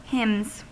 Uttal
Uttal US Okänd accent: IPA : /hɪmz/ Ordet hittades på dessa språk: engelska Ingen översättning hittades i den valda målspråket.